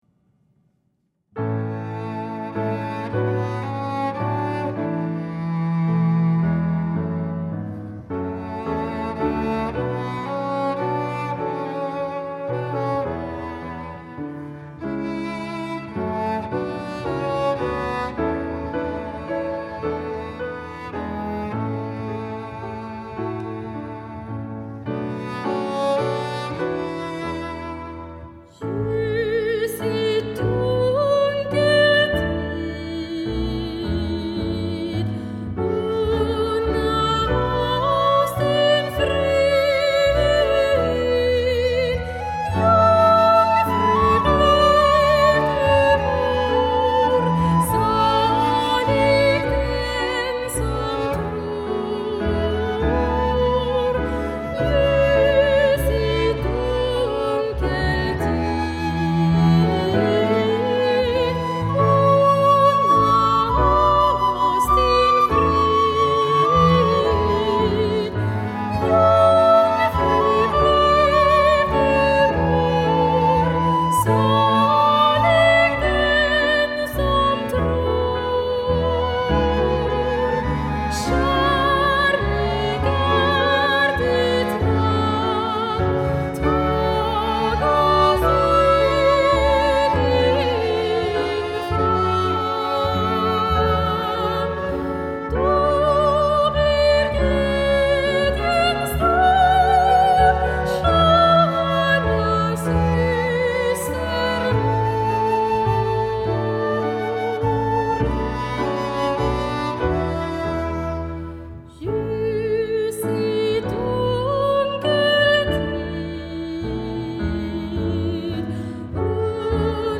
Sopran
Piano
Tvärflöjt
Cello
Kontrabas